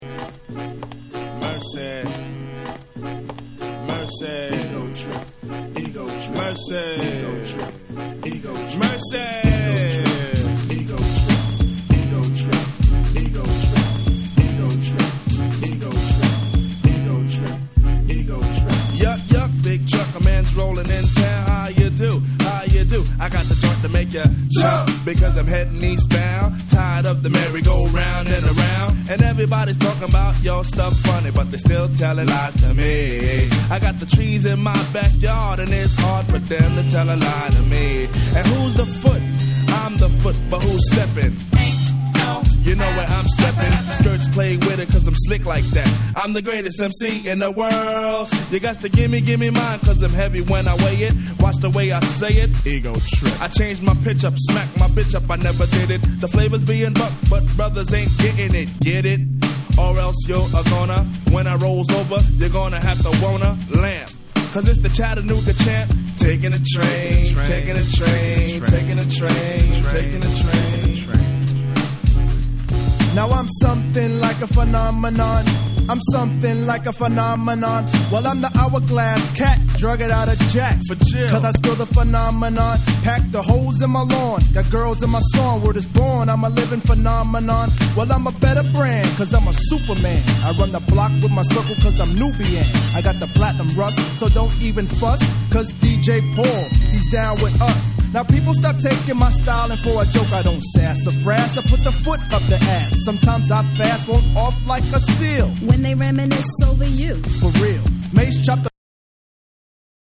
BREAKBEATS / HIP HOP